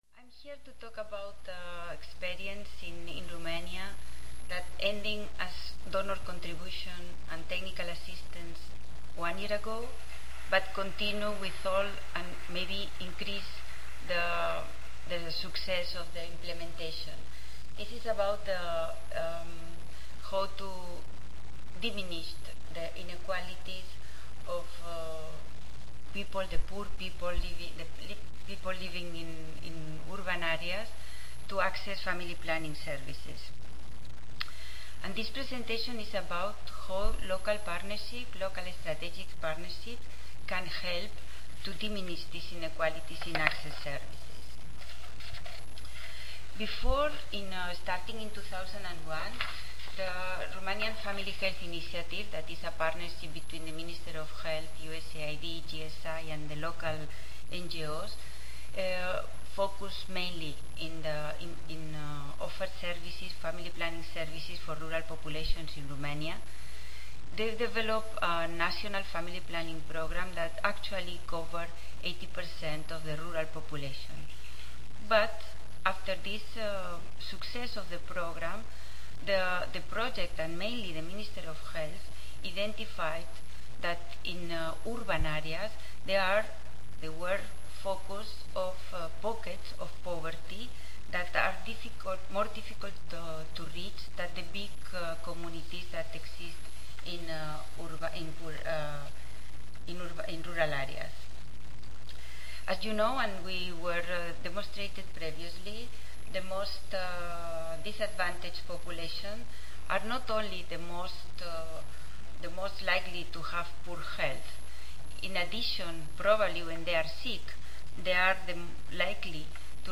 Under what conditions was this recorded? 5073.0 Policy Perspectives in International RH Wednesday, November 7, 2007: 8:30 AM Oral This session focuses on strategies to increase access to FP/RH services in developing countries. The panel presents evidence from Romania, Peru, Egypt, and Indonesia.